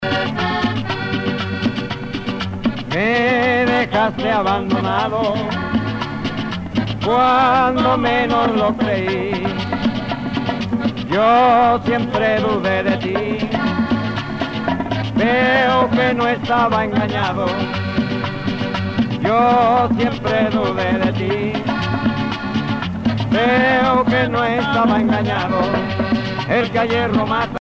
danse : porro (Caraïbe colombienne)
Pièce musicale éditée